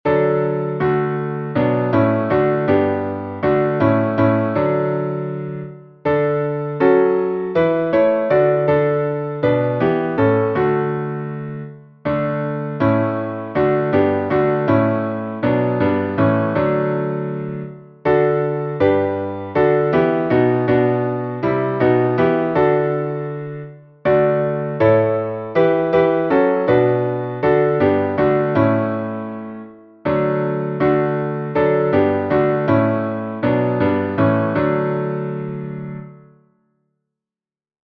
Harmonização: Claude Goudimel, 1564
Salmo 47A Métrica: Saltério de Genebra, 1551 Modo: jônio Harmonização: Claude Goudimel, 1564 Metrificação: Comissão Brasileira de Salmodia, 2010 1 1 Ó povos, batei palmas ao Senhor!
Downloads Áudio Áudio cantado (MP3) Áudio instrumental (MP3) Áudio instrumental (MIDI) Partitura Partitura 4 vozes (PDF) Cifra Cifra (PDF) Cifra editável (Chord Pro) Mais opções Página de downloads
salmo_47A_instrumental.mp3